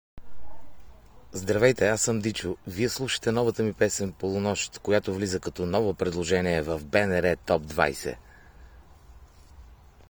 Повече за песента “Полунощ” чуйте от Дичо в интервюто, което той даде специално за слушателите на БНР Топ 20: